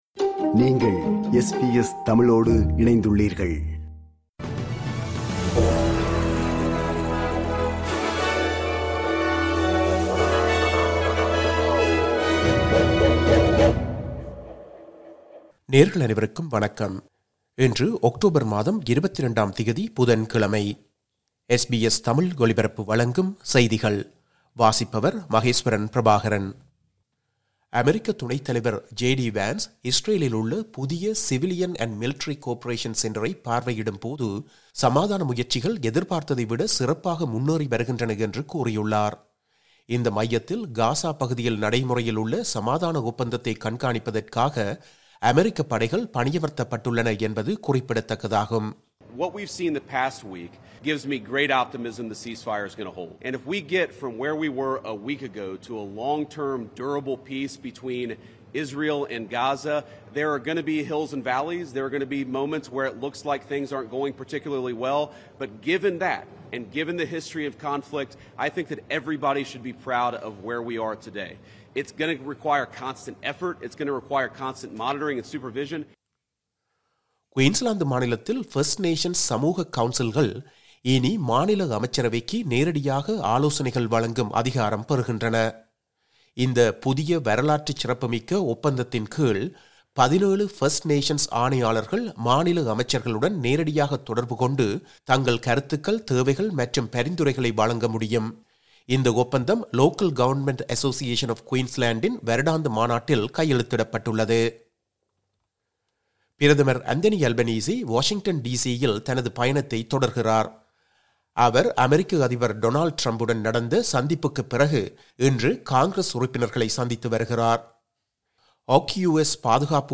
SBS தமிழ் ஒலிபரப்பின் இன்றைய (புதன்கிழமை 22/10/2025) செய்திகள்.